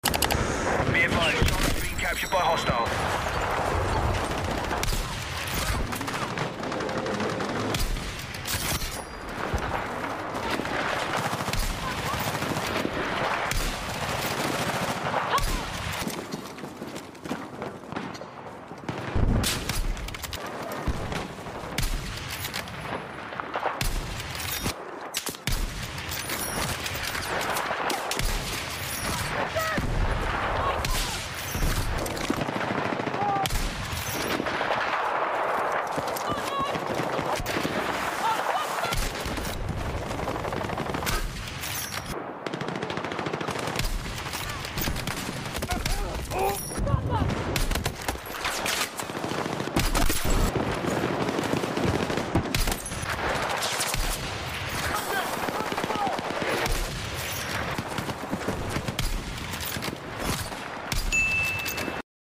*ASMR* 🎯 Battlefield 6 Sniper sound effects free download